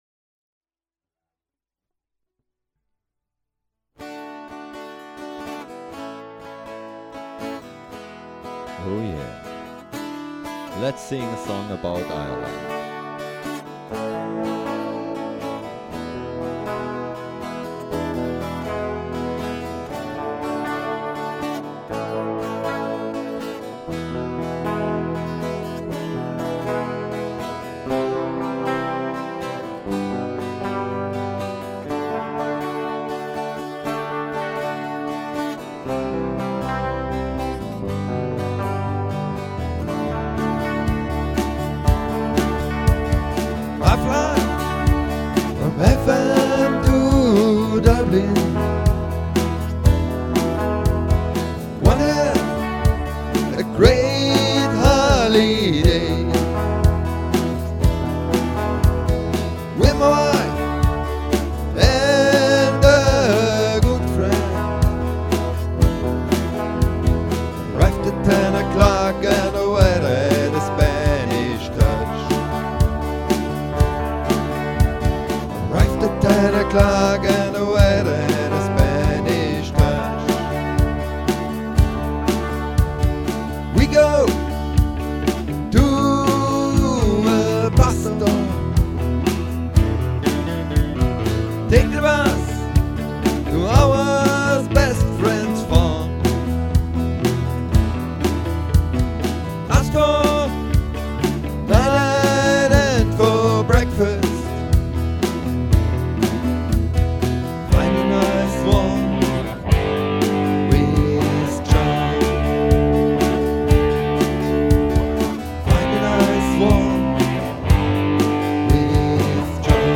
Ireland.....mp3 Datei mit Vocals